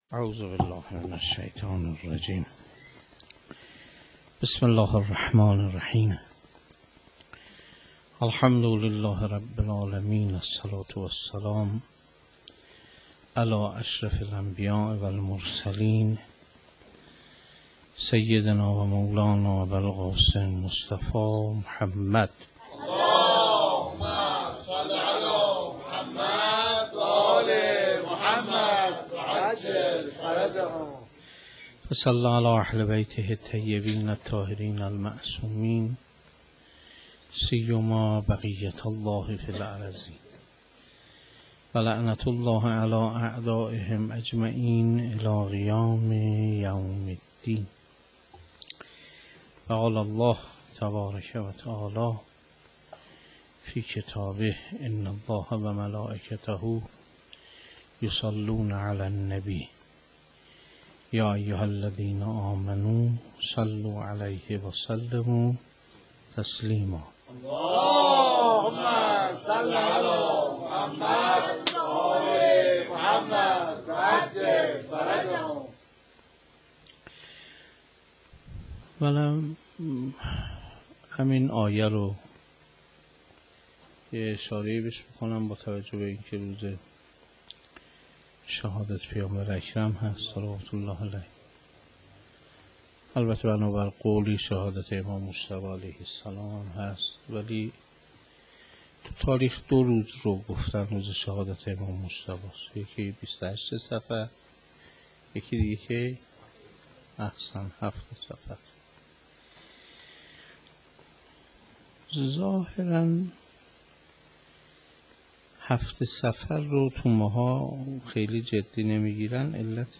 سخنرانی شام شهادت امام حسن (ع)